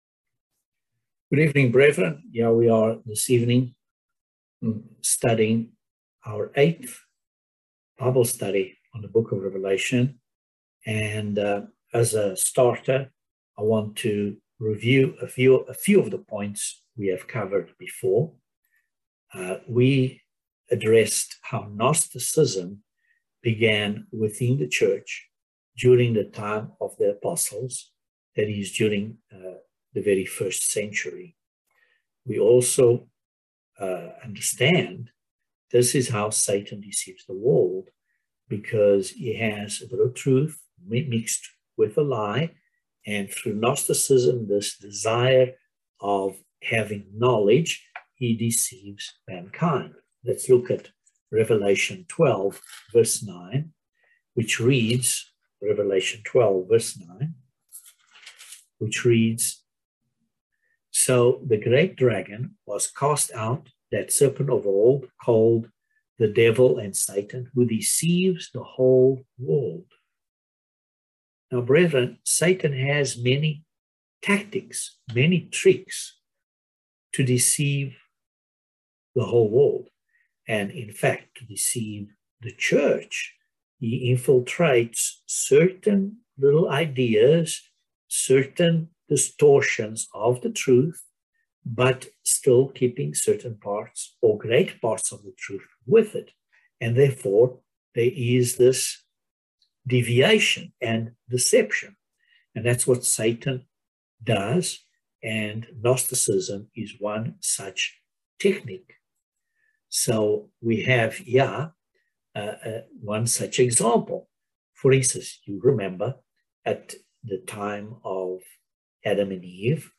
Bible Study no 8 of Revelation